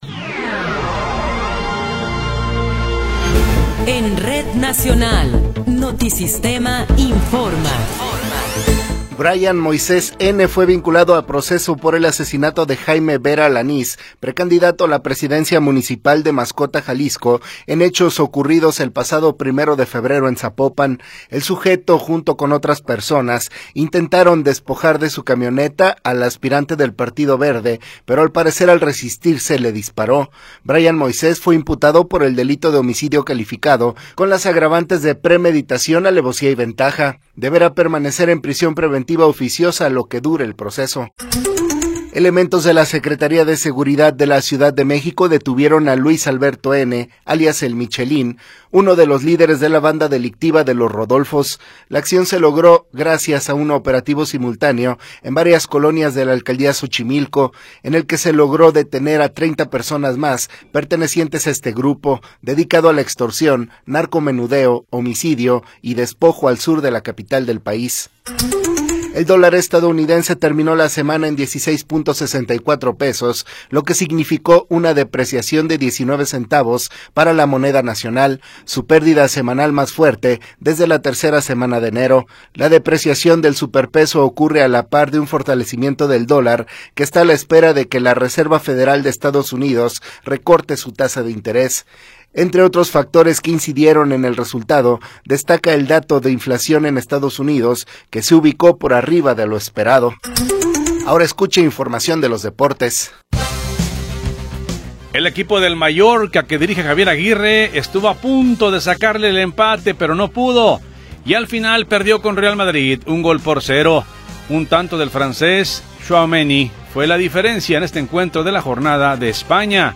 Noticiero 13 hrs. – 13 de Abril de 2024